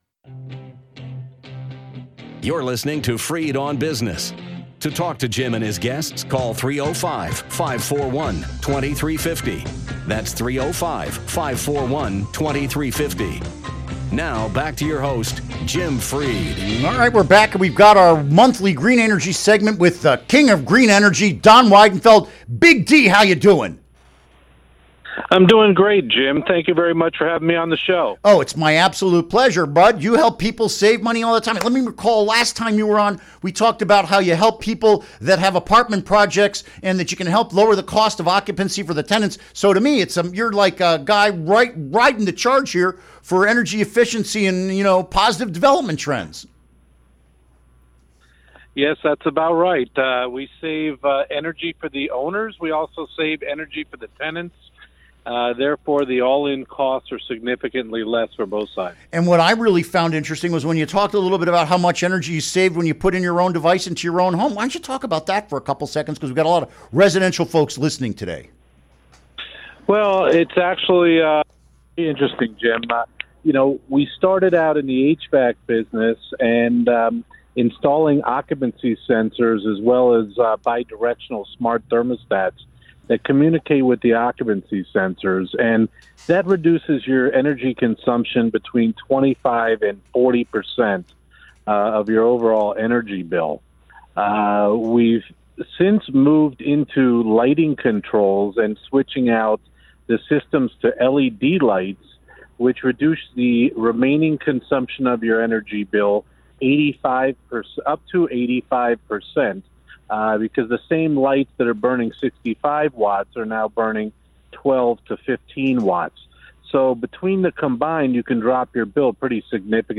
Interview Segment Episode 390: 10-20-16 Download Now!